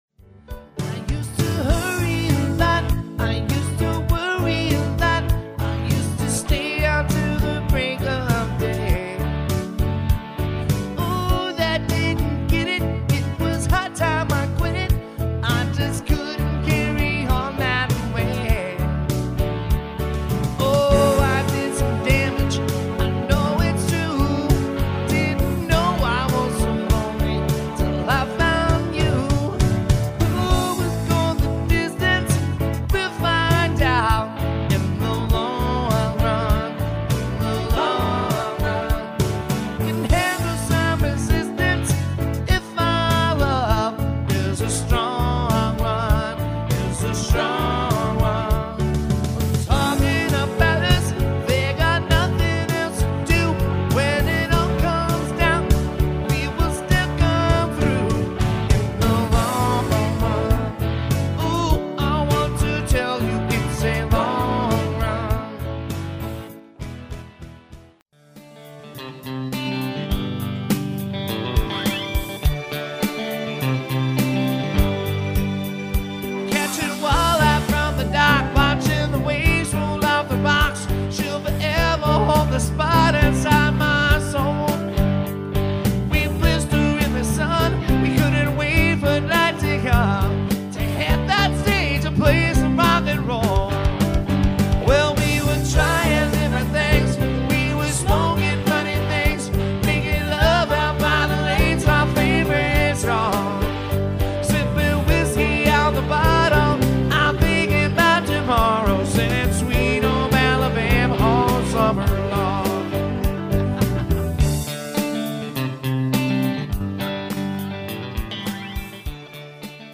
Country Rock
The mix may not always be perfect, you may here some
minor distortion, you will here some background noise.